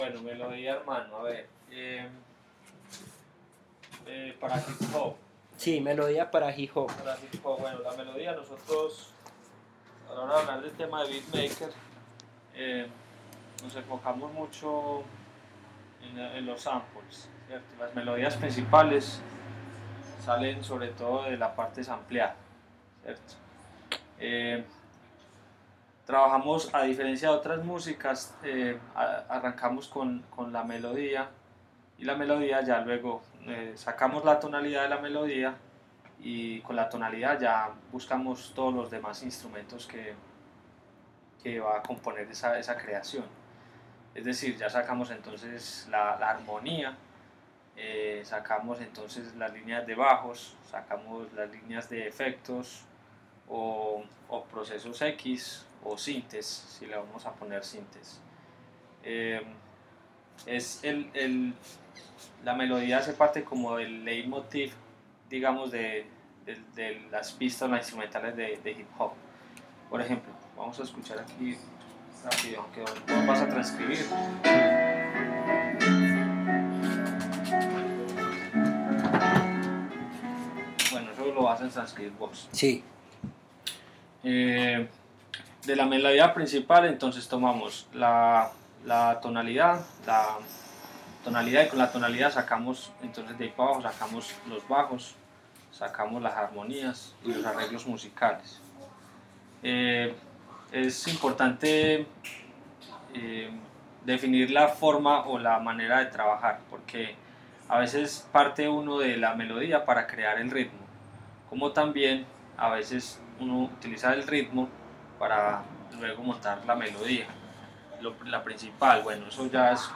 Audio de la clase